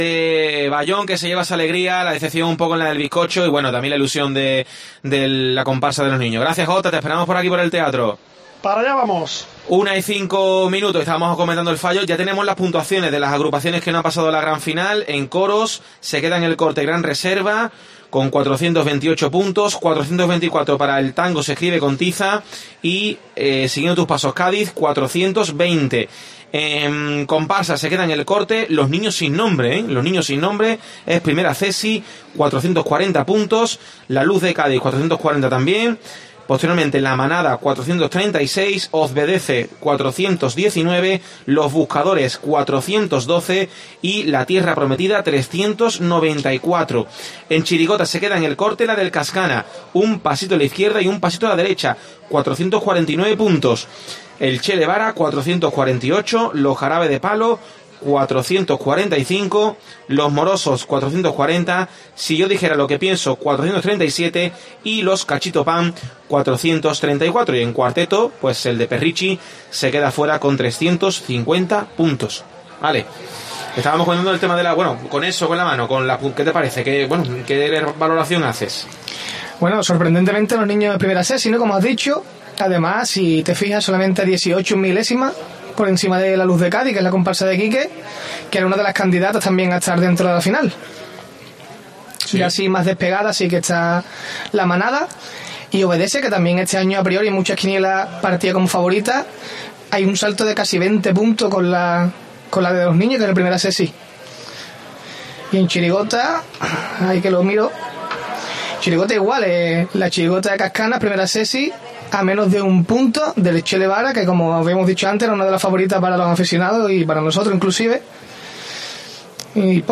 Desde el palco de COPE en el Falla